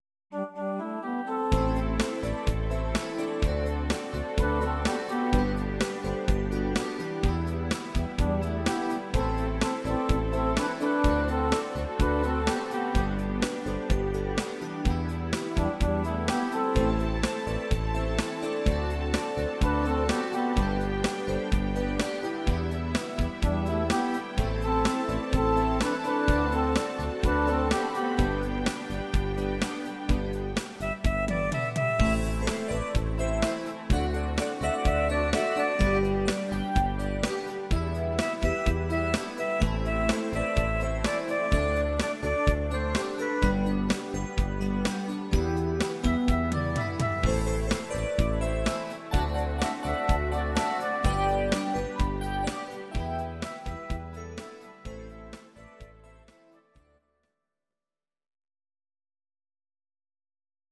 These are MP3 versions of our MIDI file catalogue.
Please note: no vocals and no karaoke included.
Your-Mix: Pop (21635)